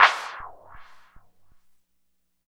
88 MD CLAP-L.wav